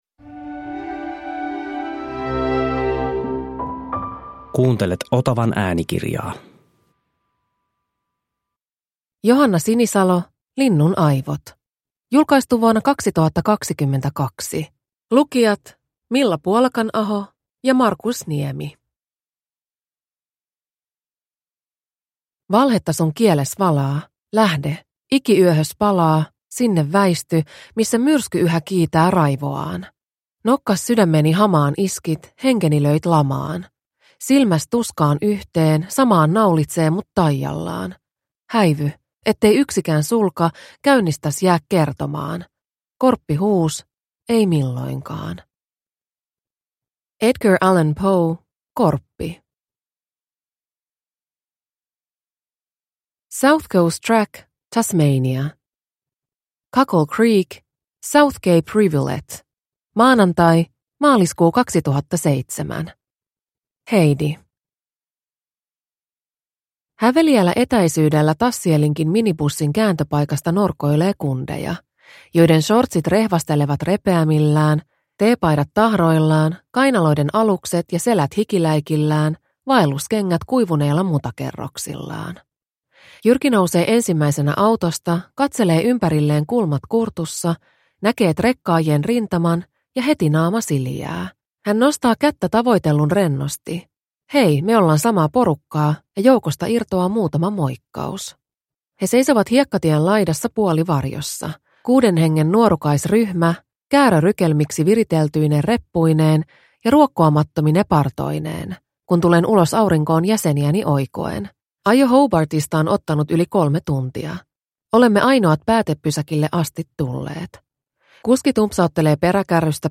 Linnunaivot – Ljudbok – Laddas ner